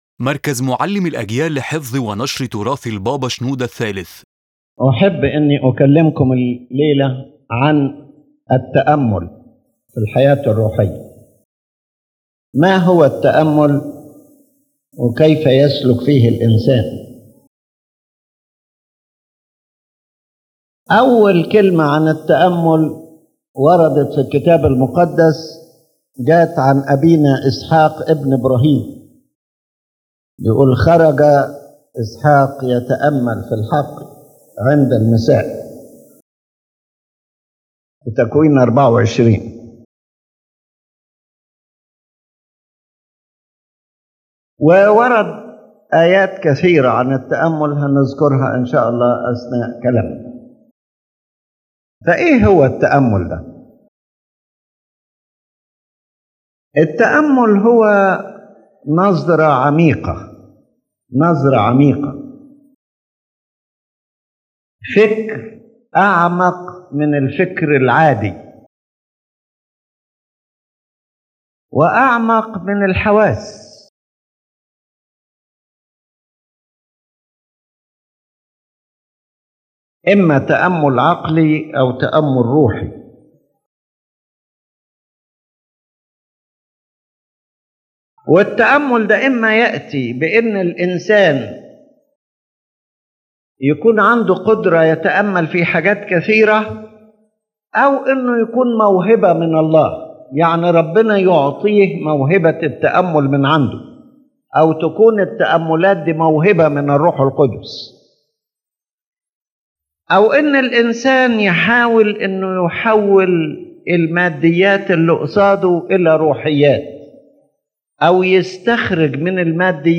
His Holiness Pope Shenouda III, in this sermon, speaks about meditation as a fundamental part of spiritual life, explaining that it is not mere thought or imagination but a deep gaze filled with the Spirit that opens the mind and heart to receive divine knowledge.